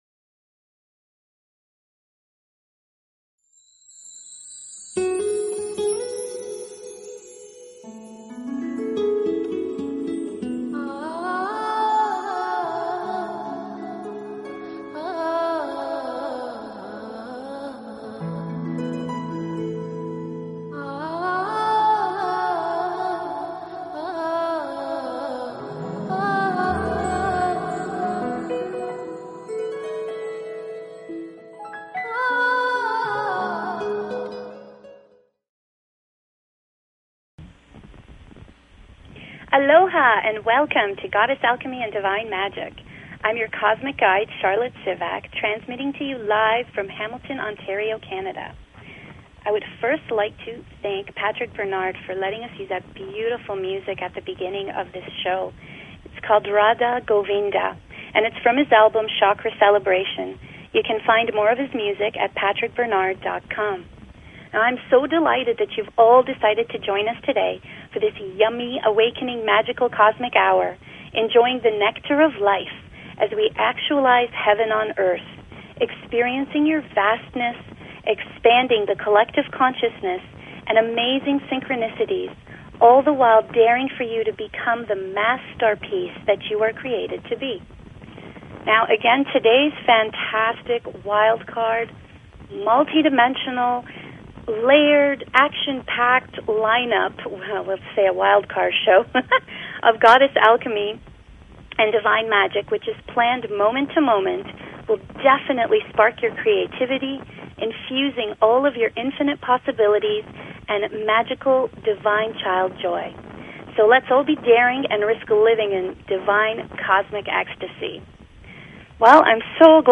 Each broadcast is infuses with ascension Light body activations, transmissions, shadow wisdom transformations and meditative journeys assisting with awakening, anchoring, and actualization of